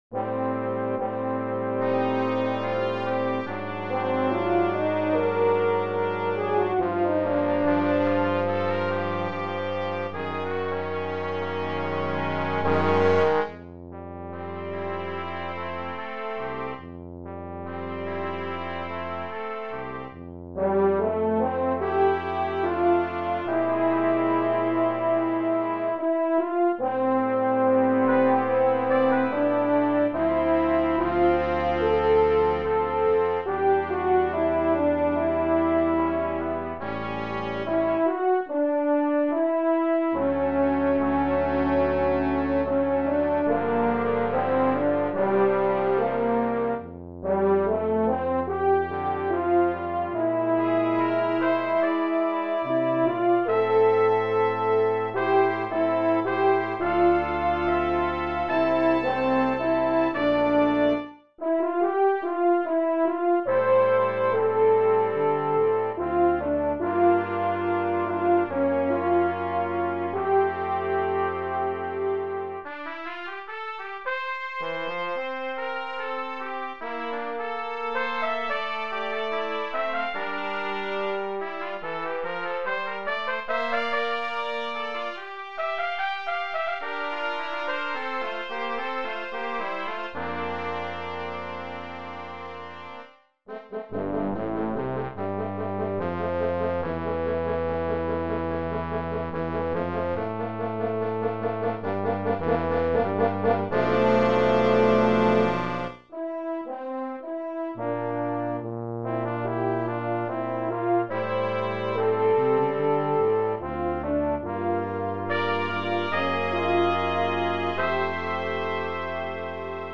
Brass Quintet
Listen to synthesized versions.